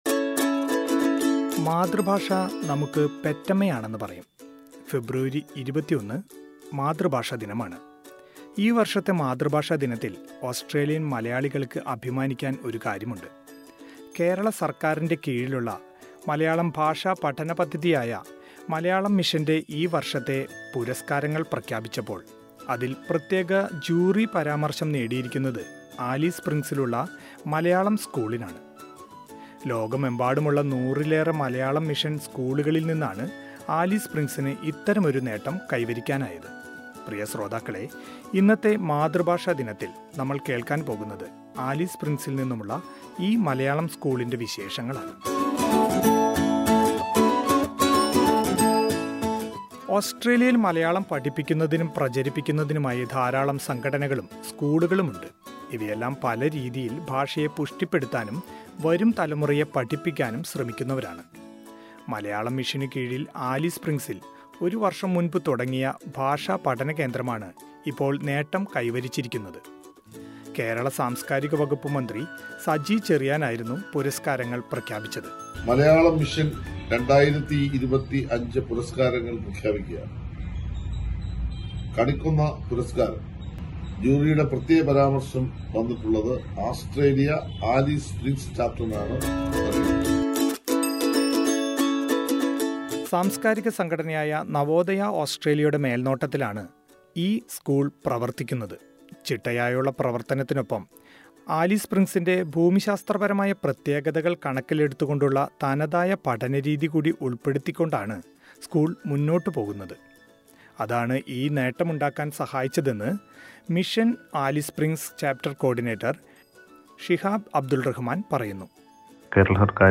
മാതൃഭാഷ ദിനത്തോടനുബന്ധിച്ചു മികച്ച മലയാളം മിഷൻ ചാപ്റ്ററിനുള്ള കേരള സർക്കാരിന്റെ പ്രത്യേക ജൂറി പരാമർശം കരസ്ഥമാക്കിയിരിക്കുകയാണ് ഓസ്‌ട്രേലിയയിലെ ആലിസ് സ്പ്രിങ്സ് മലയാളം മിഷൻ. ഈ മലയാളം സ്കൂളിന്റെ പ്രവർത്തകർ അവരുടെ പ്രവർത്തനങ്ങളെ കുറിച് പറയുന്നത് കേൾക്കാം മുകളിലെ പ്ലെയറിൽ നിന്നും...